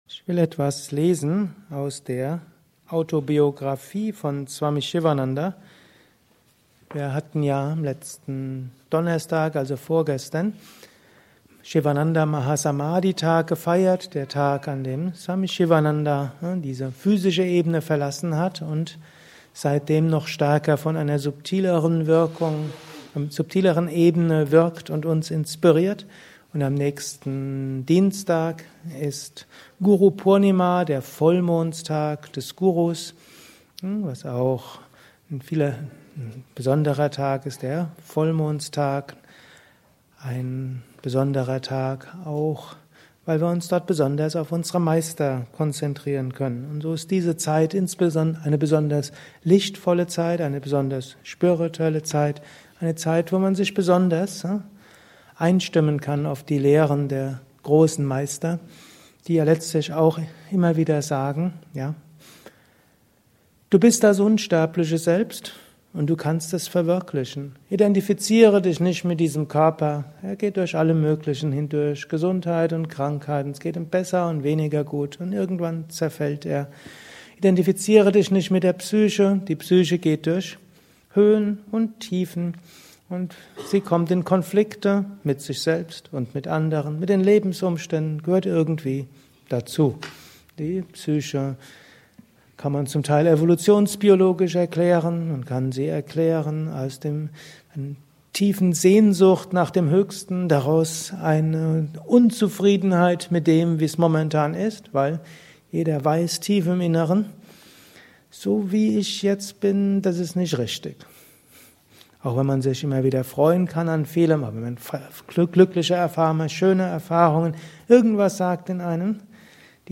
Gelesen im Anschluss nach einer Meditation im Haus Yoga Vidya Bad Meinberg.
Lausche einem Vortrag über: Tage nach Swami Sivanandas Mahasamadhi Tag